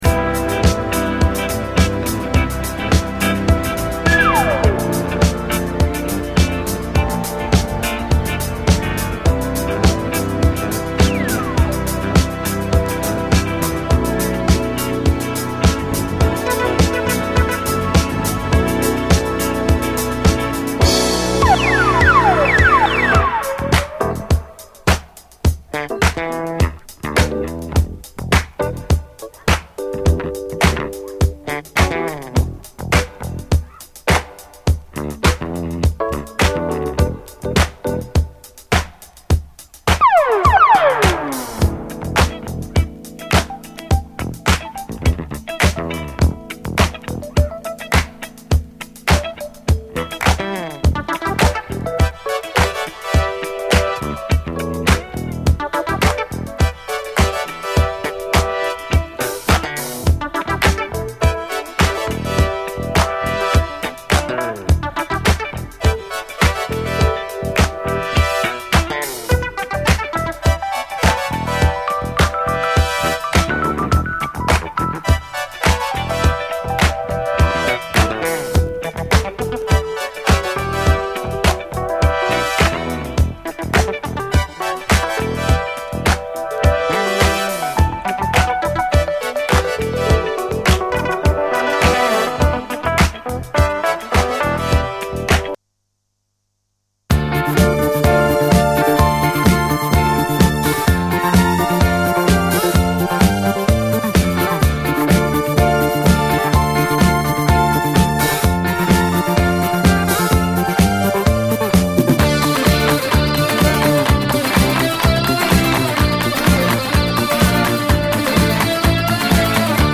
A面は、ディスコ・ヒットのイントロのみを矢継ぎ早につないだ、ユーロ産の企画モノ・ディスコ！
ひたすらディスコ・ヒットのイントロのみを矢継ぎ早にカヴァーしてつないでみせた、ユーロ産の企画モノ・ディスコ！
ダビー＆スペイシーなシンセが飛び交う今風なダウンビート・メロウ・ディスコで必聴！